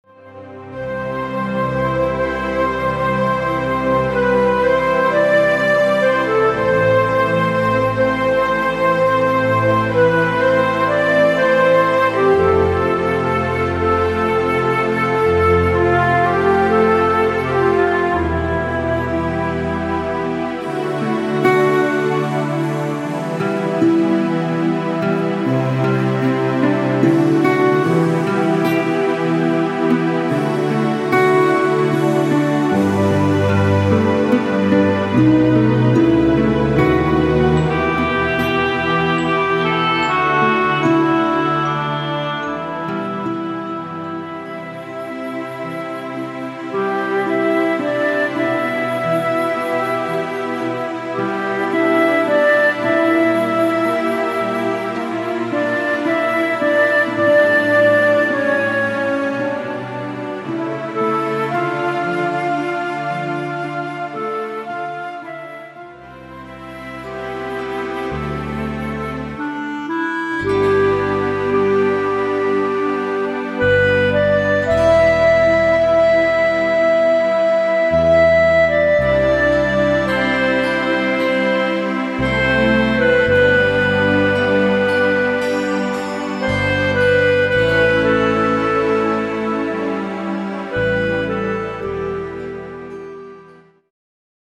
Mélodies populaires pour les adieux